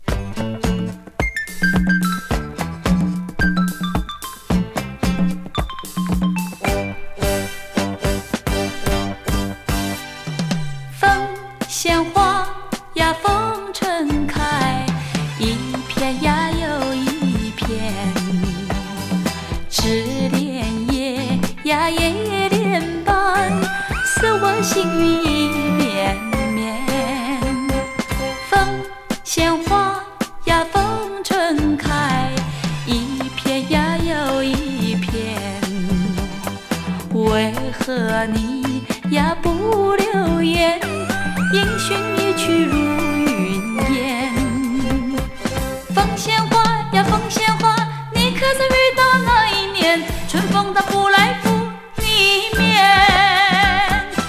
グルーヴィー・ポップス
両曲共にシンセが良い味出してます。